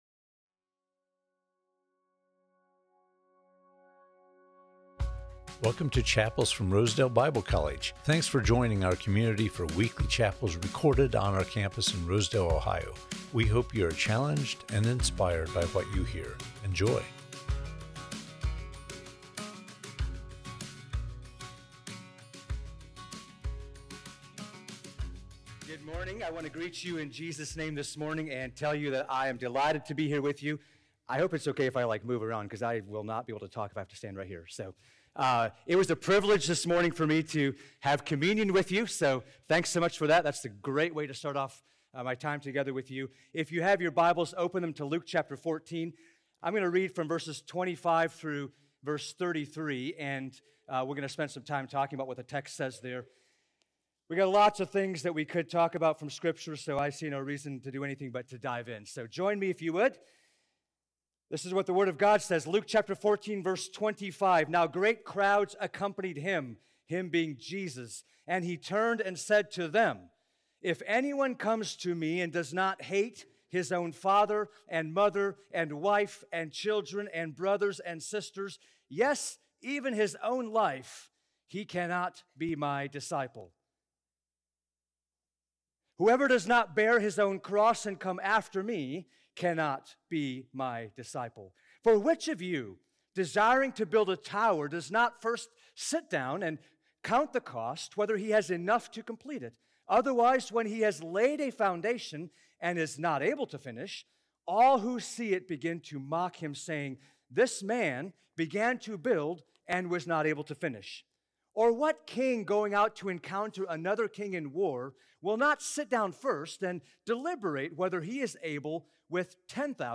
Chapels from Rosedale Bible College The Cost of Being a Disciple